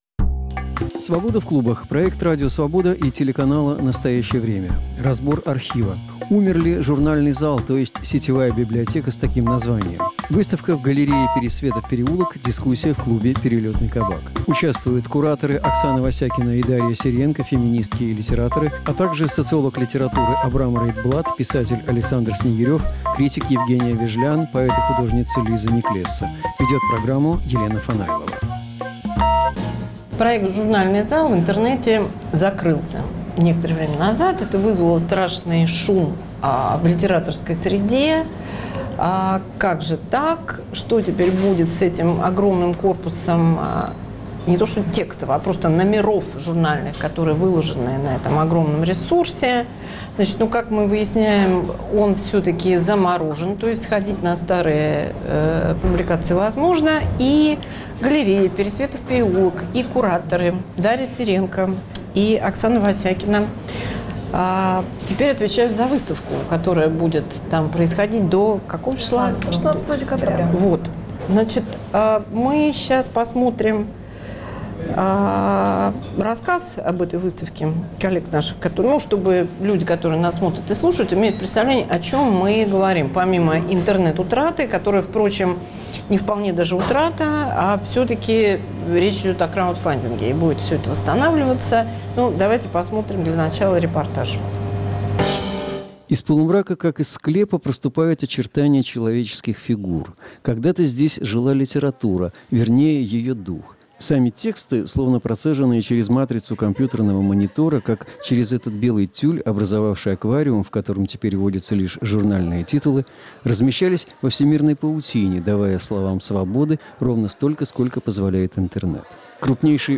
Кому нужны литературные "толстые" журналы, особенно их архив в интернете? Обсуждают социолог литературы и писатели.